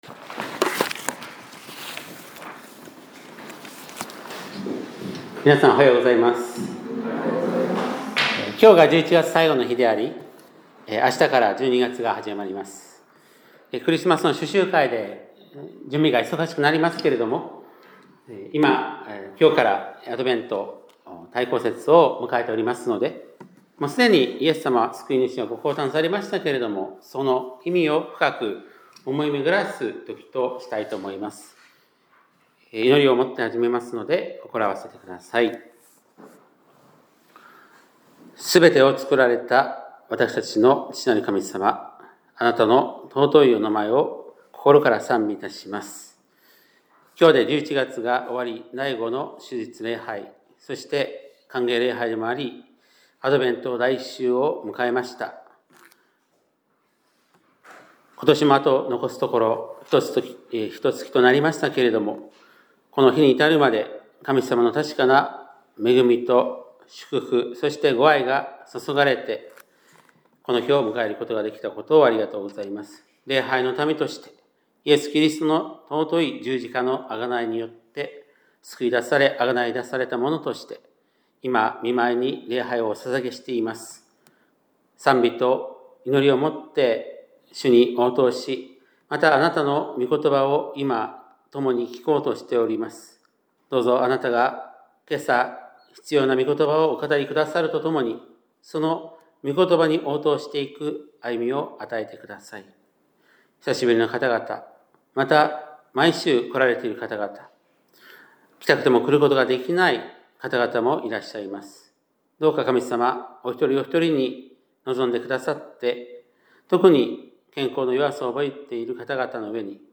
2025年11月30日（日）礼拝メッセージ